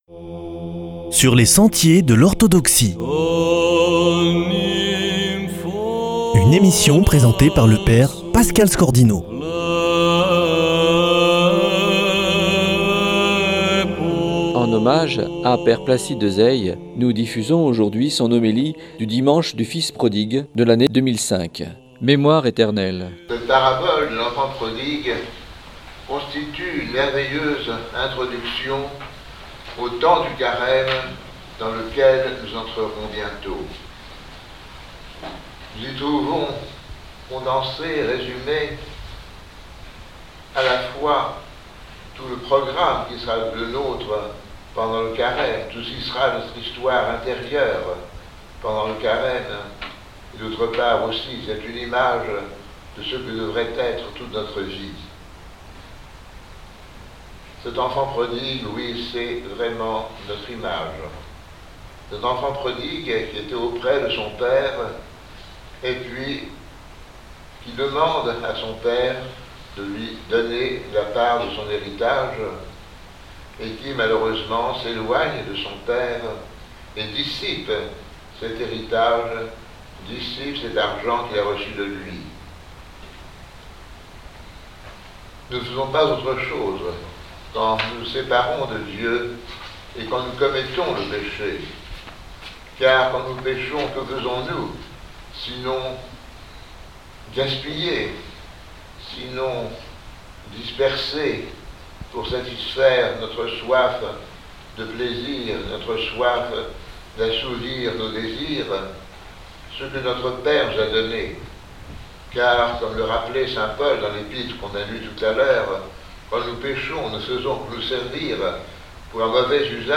homélie 2005 sur la parabole de l'Enfant Prodigue